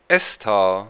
"ess-star"...